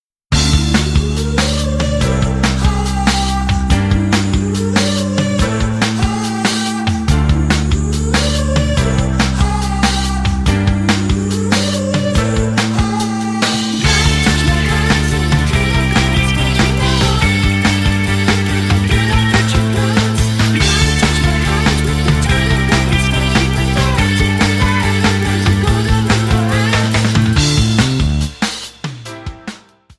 Eb
Backing track Karaoke
Pop, 2000s